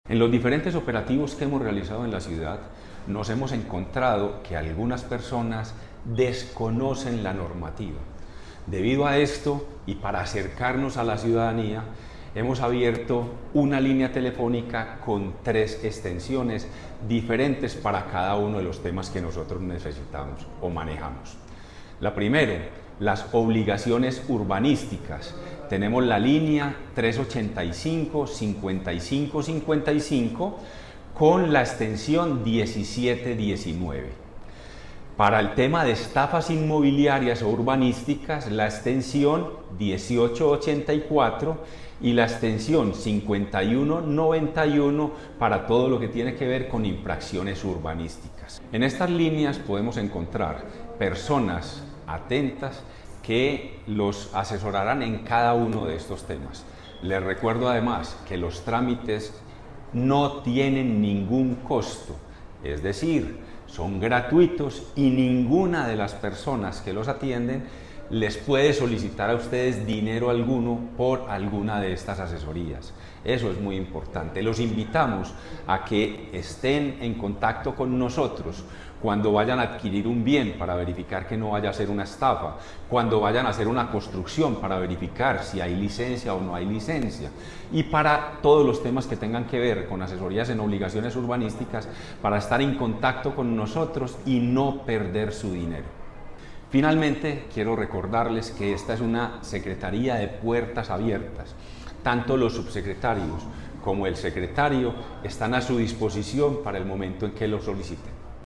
Palabras de Juan Manuel Velásquez, secretario de Gestión y Control Territorial